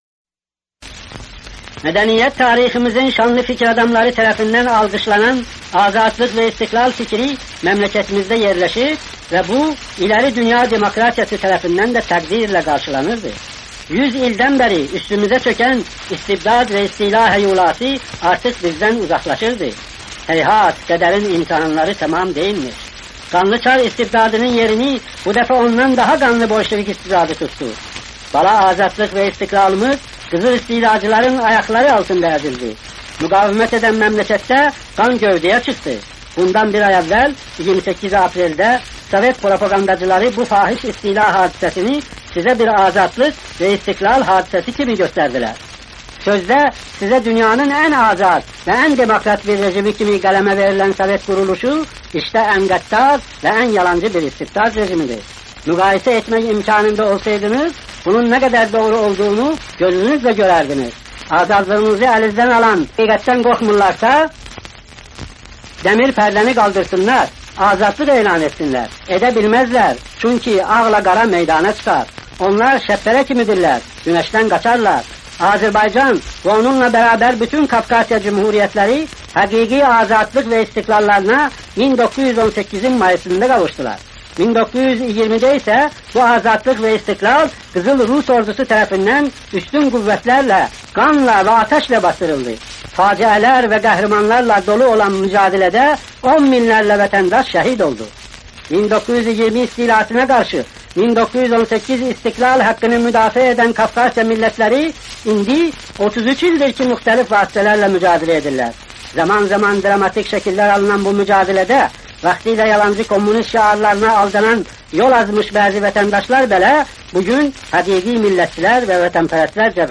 Mühacirətdə olarkən Azərbaycan Xalq Cümhuriyyətinin banisi Məhəmməd Əmin Rəsulzadə 1953-cü il mayın 28-də Amerikanın Səsi radiosu vasitəsilə Azərbaycan xalqına müraciət edib.
Amerikanın Səsinin arxivində qorunub saxlanan müraciətini təqdim edirik: